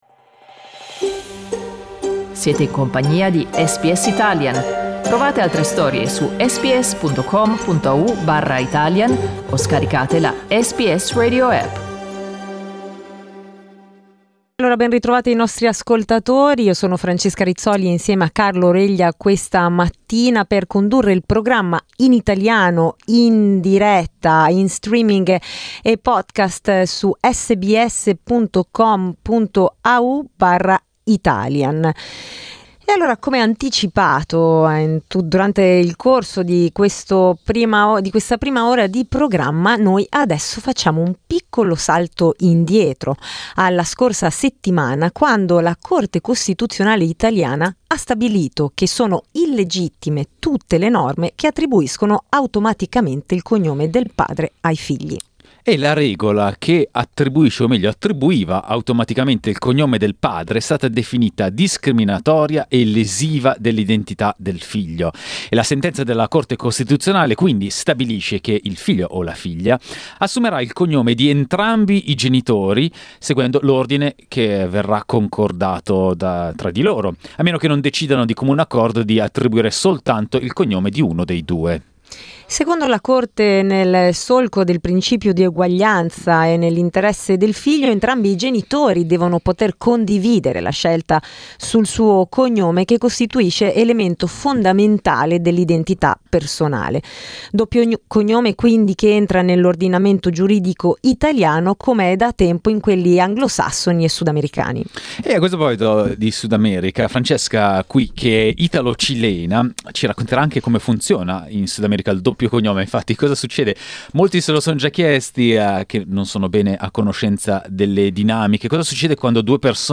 Ascoltate il dibattito su SBS Italian.